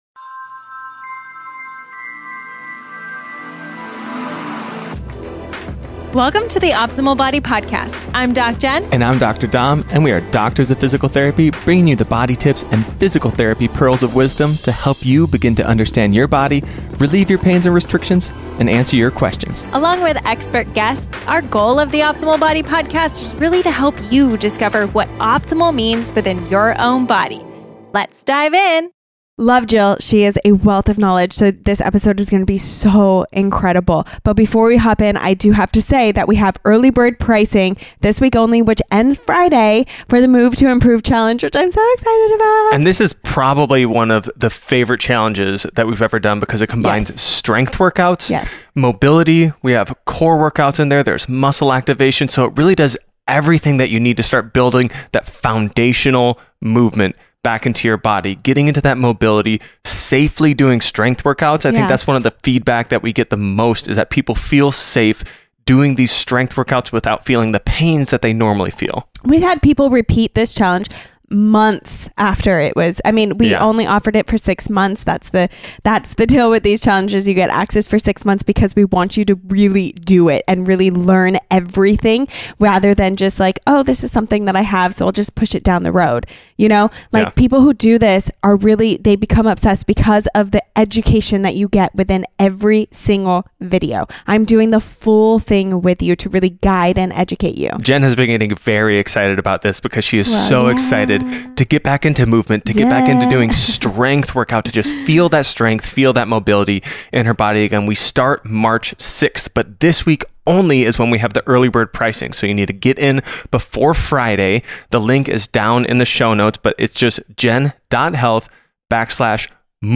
What You Will Learn In This Interview